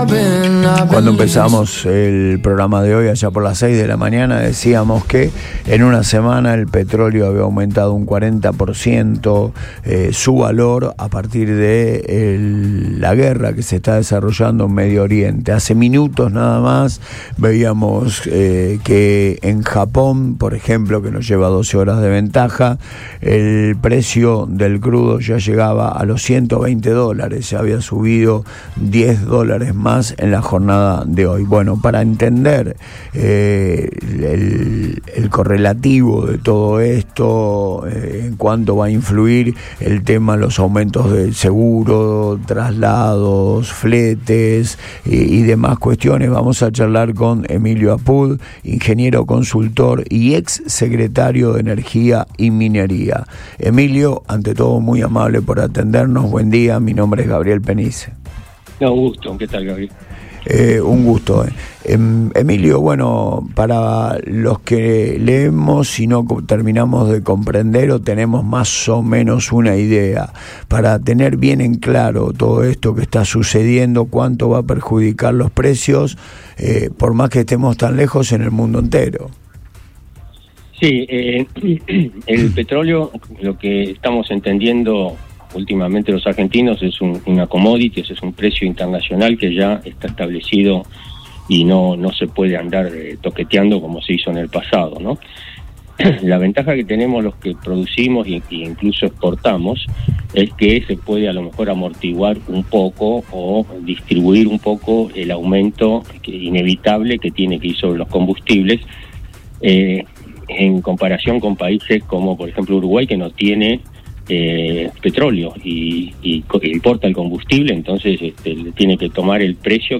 En diálogo en “Antes de Todo” por Radio Boing, el ingeniero y ex secretario de Energía, Emilio Apud, analizó el impacto que esta escalada tendrá en los surtidores locales.